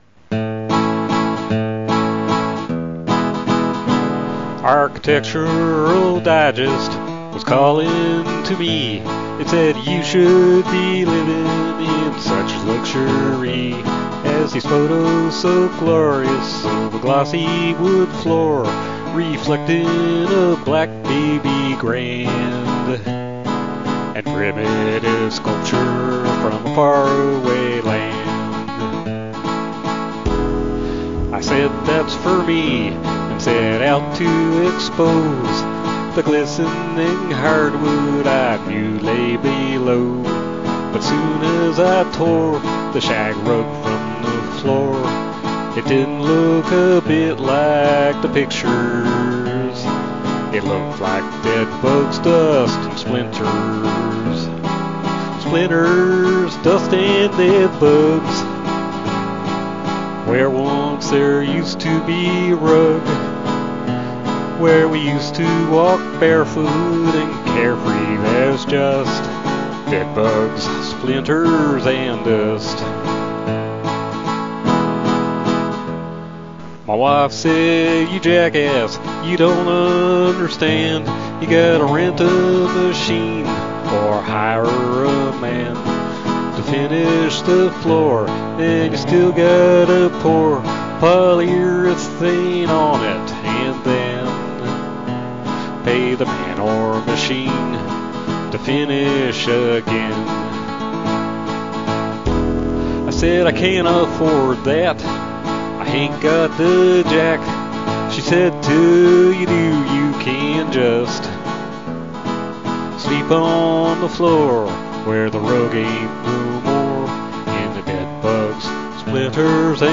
country waltz, male voice